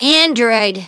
synthetic-wakewords
synthetic-wakewords / android /ovos-tts-plugin-deepponies_Chie Satonaka_en.wav
ovos-tts-plugin-deepponies_Chie Satonaka_en.wav